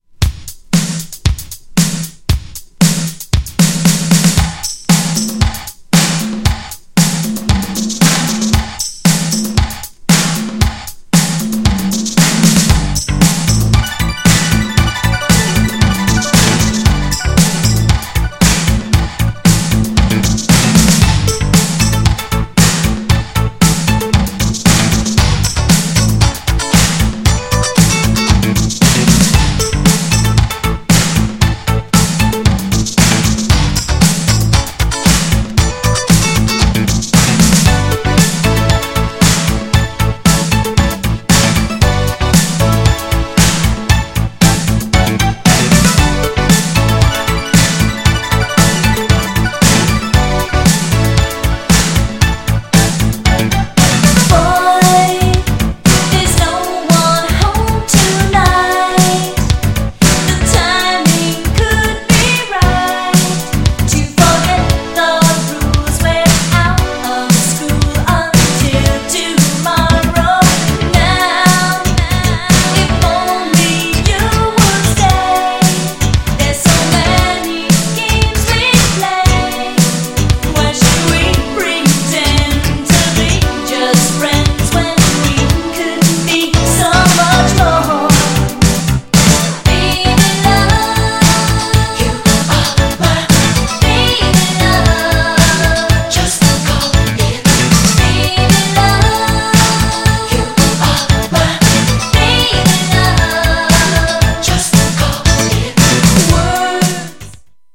GENRE Dance Classic
BPM 126〜130BPM